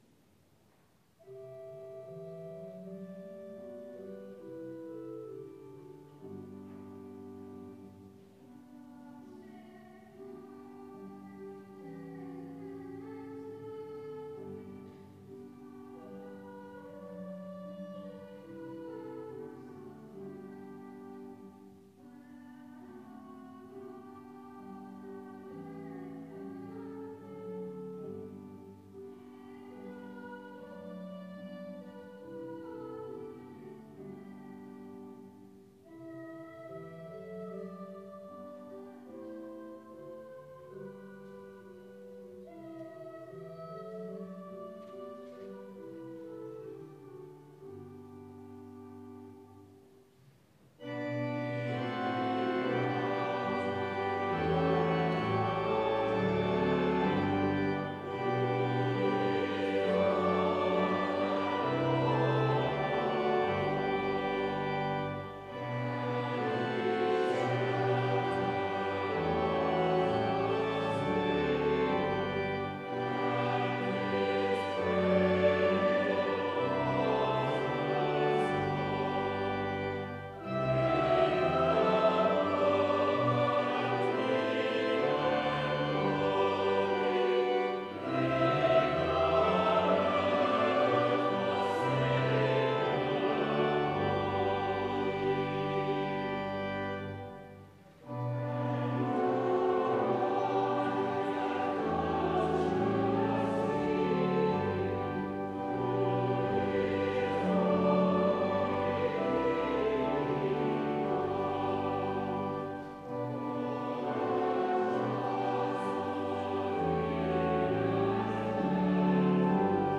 Full Service Audio
The Scots’ Church Melbourne 11am Service 20th of December 2020